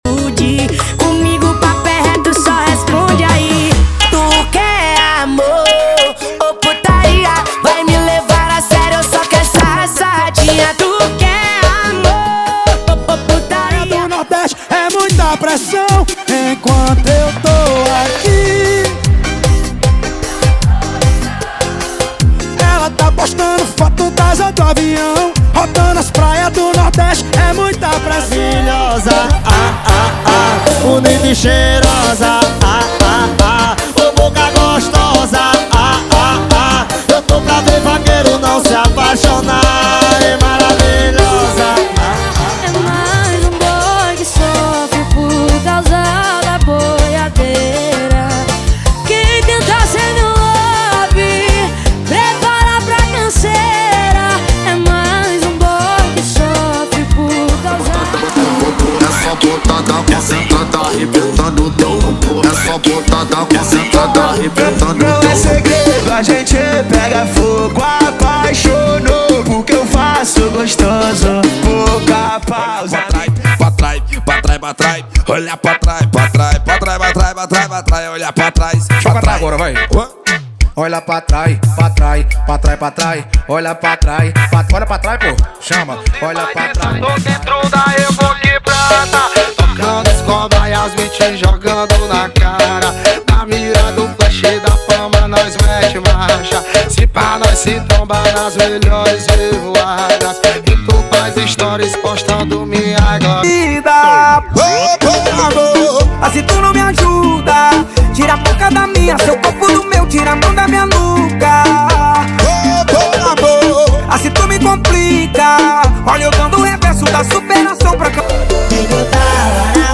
• Forró Pisadinha = 100 Músicas
• Sem Vinhetas
• Em Alta Qualidade